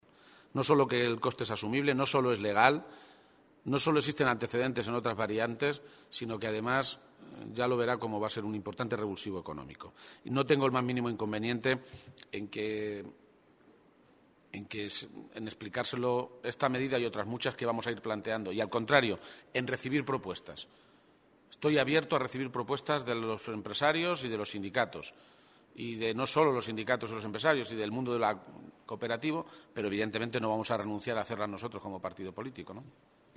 Se pronunciaba así García-Page, esta mañana, en Toledo, a preguntas de los medios de comunicación, que le pedían una valoración sobre las informaciones publicadas hoy en un medio de comunicación nacional que señalan a que el auto del juez Ruz, conocido el pasado miércoles, sobre la llamada trama Gürtel, acredita otras poisbles mordidas de la empresa Sufi para hacerse con contratos públicos en municipios gobernados por el PP como Madrid, Salamanca o Majadohanda.
Cortes de audio de la rueda de prensa